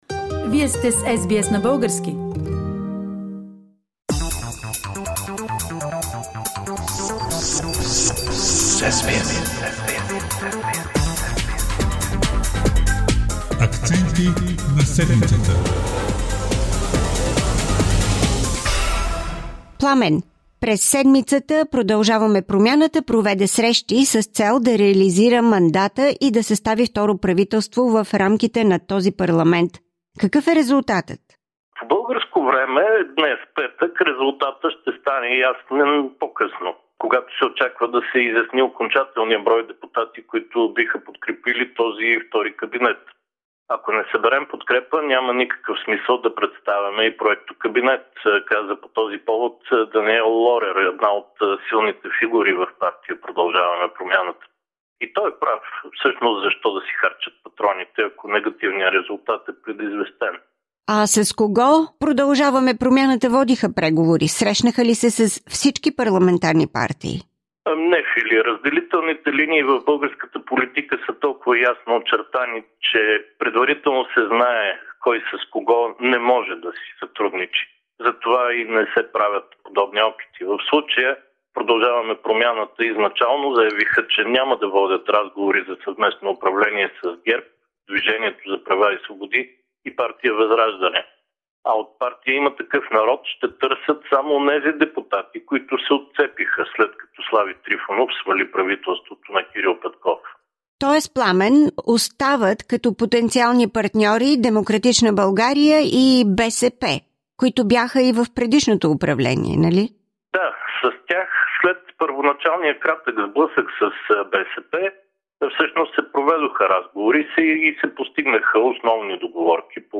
If a second government is formed, the prime minister could be the other leading figure of the party Continuing the Change - Asen Vassilev. Political analysis